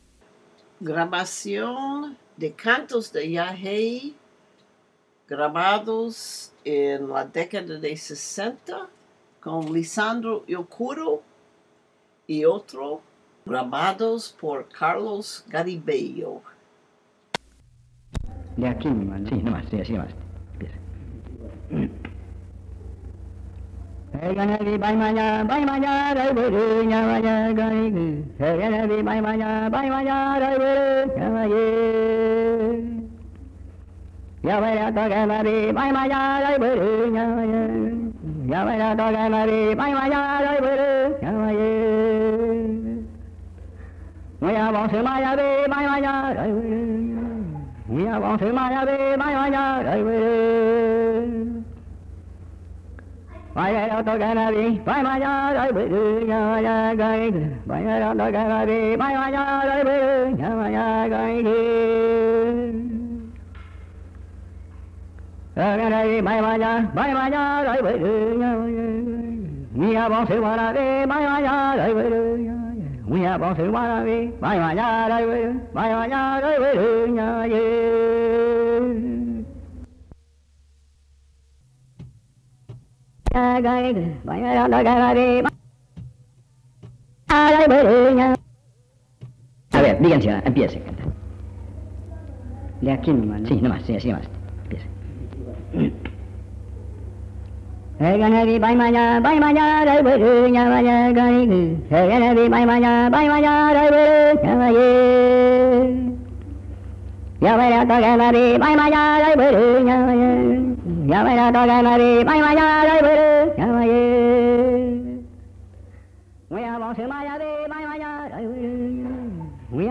Cantos_yaje.mp3 (4.46 MB)
San José, río Putumayo (Colombia)